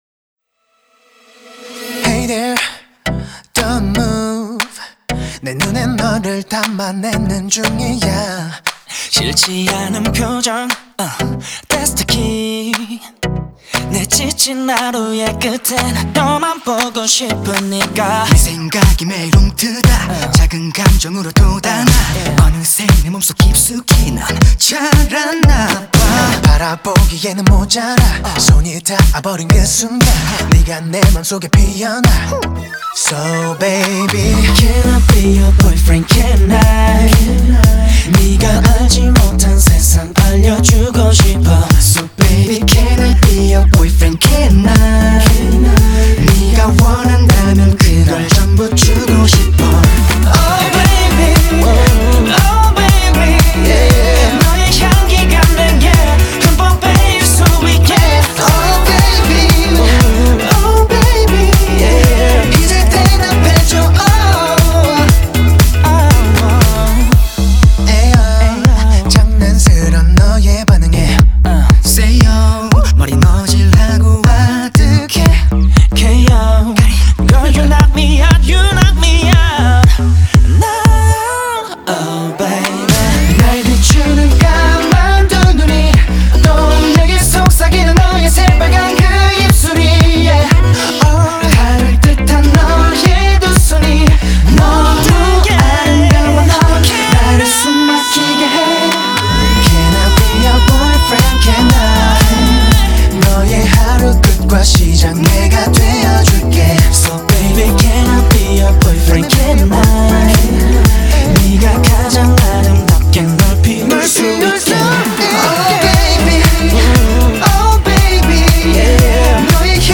BPM118
Audio QualityMusic Cut